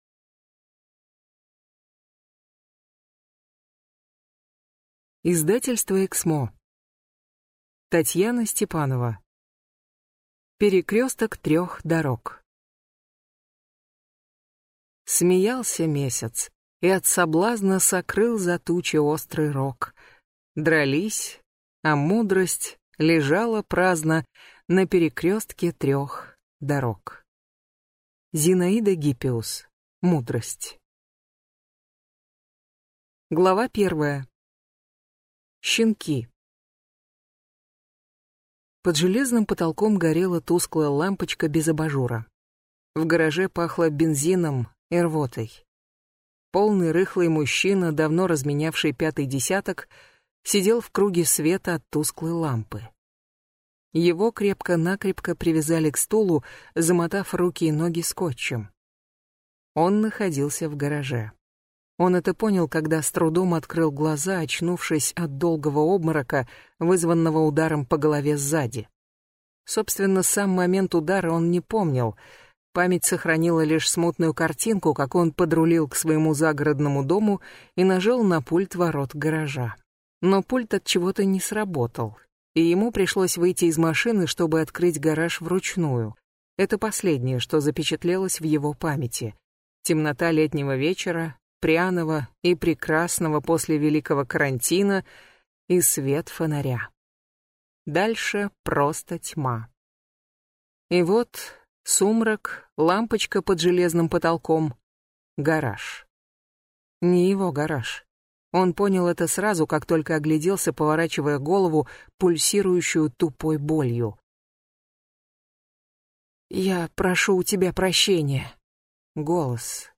Аудиокнига Перекресток трех дорог | Библиотека аудиокниг